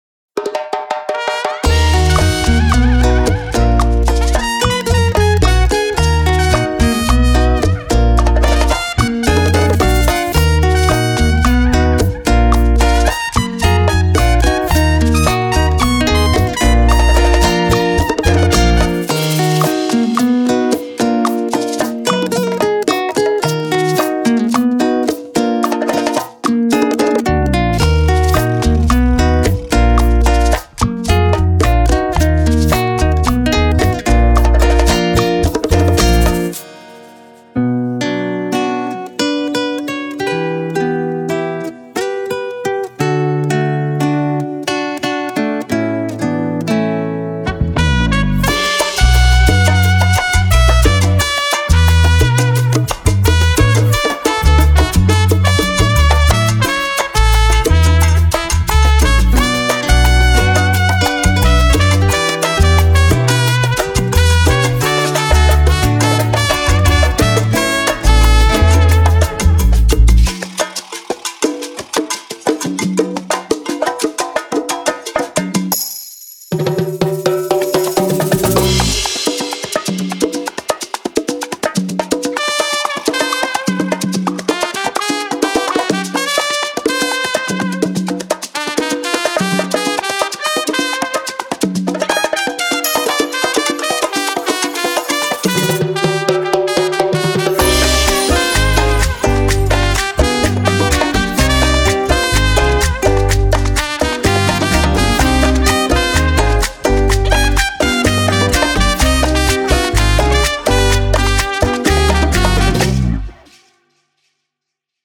Genre:Latin
デモサウンドはコチラ↓
60 Percussion Loops
25 Guitar Loops
5 Trumpet Loops